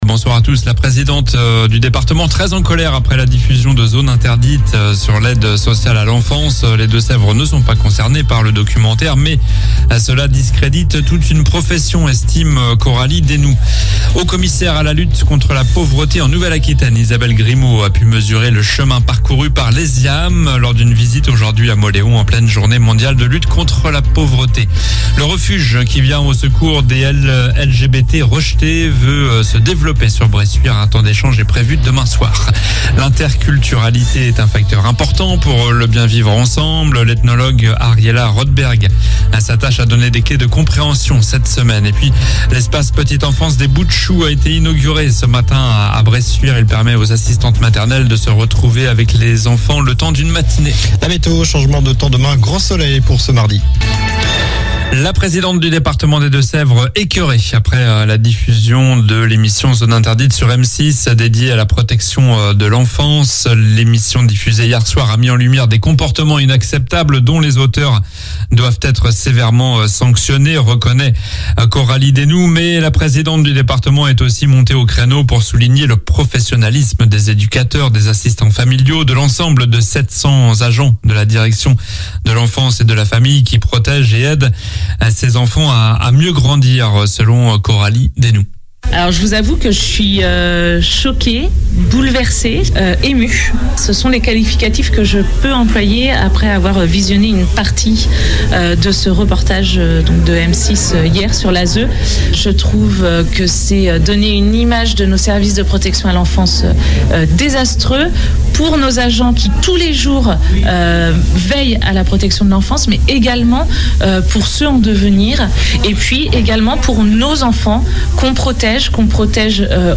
Journal du lundi 17 octobre (soir)